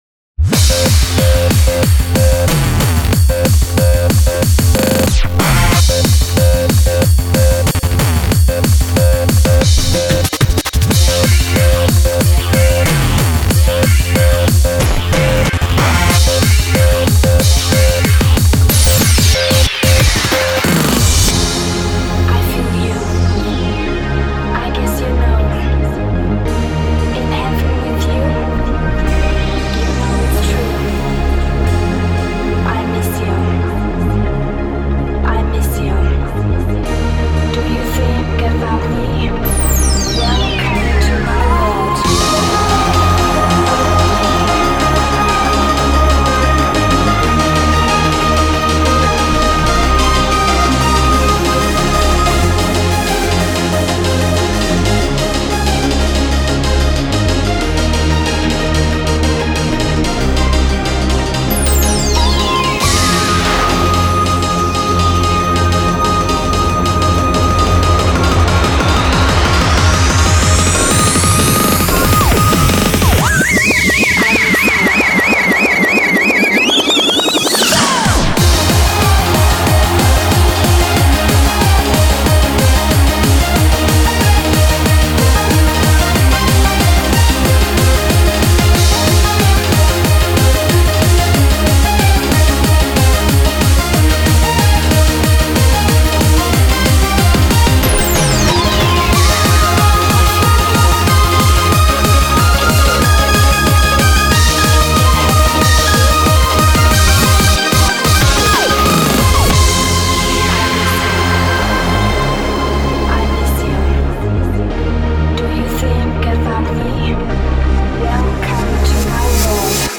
BPM185
Audio QualityPerfect (Low Quality)